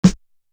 Electric Chair Snare.wav